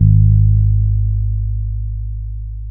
-MM DUB  G#2.wav